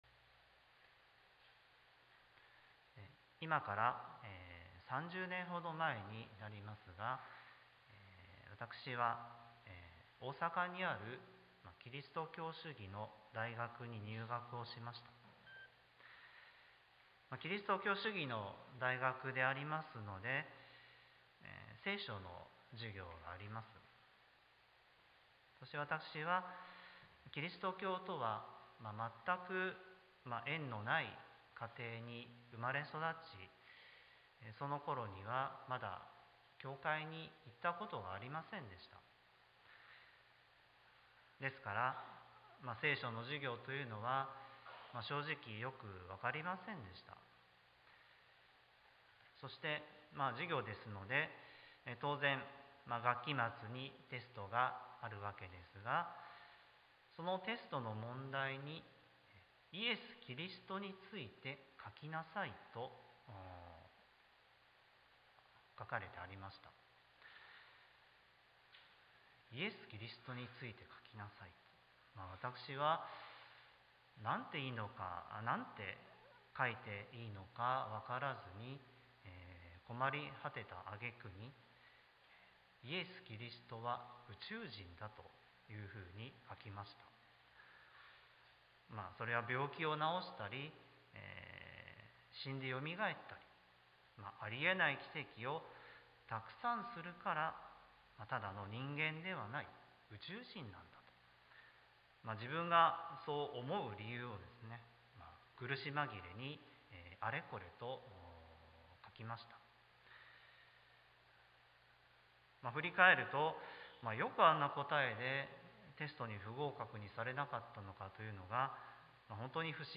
sermon-2021-09-26